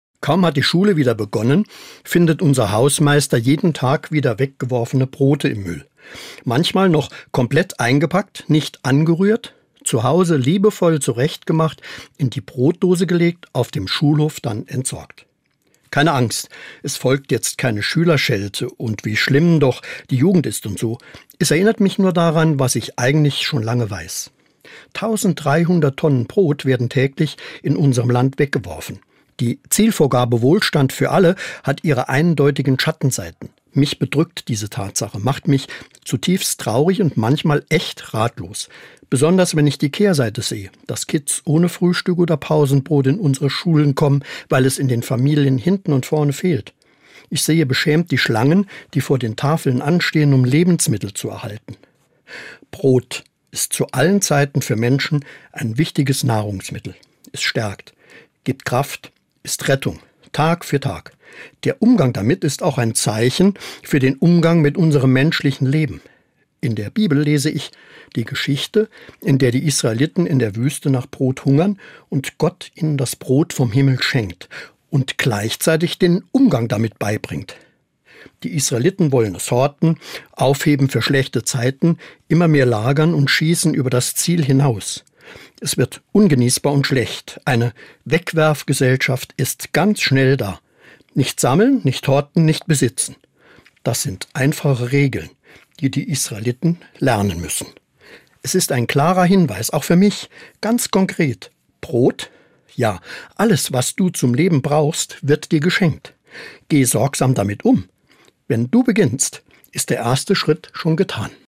Das tägliche Wort zum Alltag aus christlicher Sicht, abwechselnd von der katholischen und der evangelischen Kirche